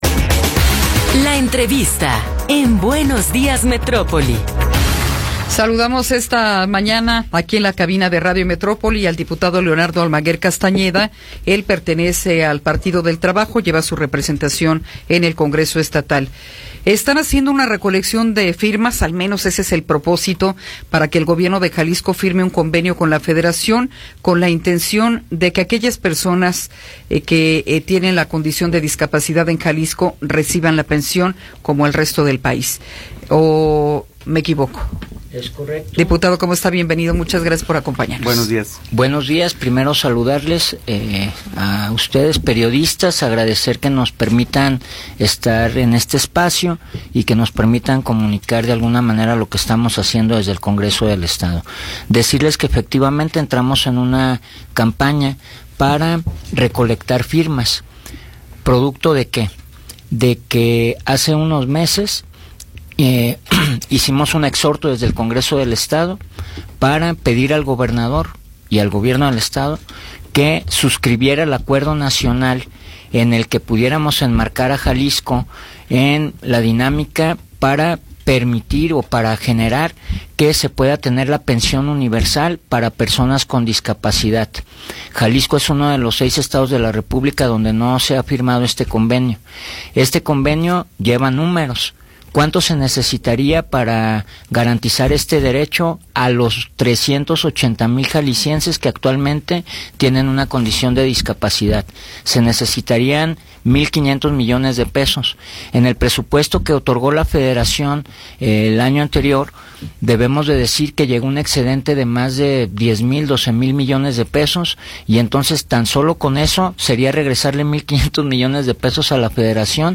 Entrevista con Leonardo Almaguer Castañeda
Leonardo Almaguer Castañeda, diputado local, nos habla sobre la recolección de firmas pera conseguir la pensión para personas con discapacidad.